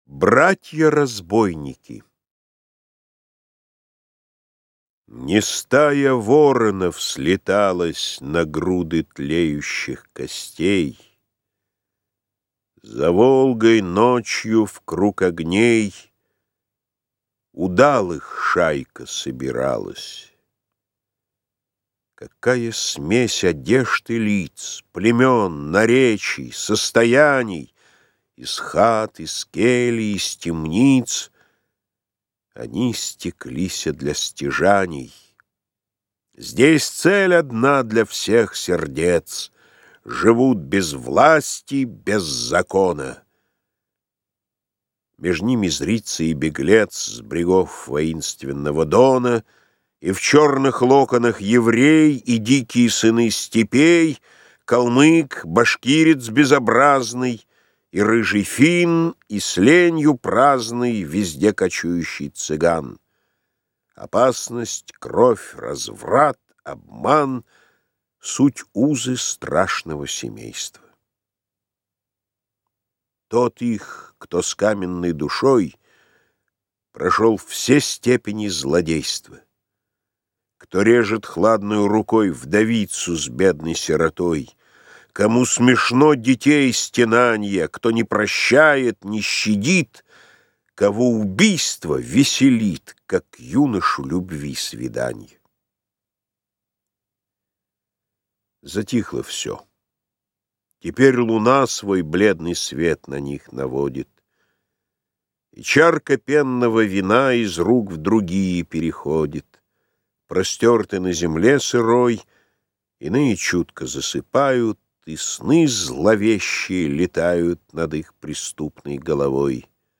Аудиокнига Поэмы | Библиотека аудиокниг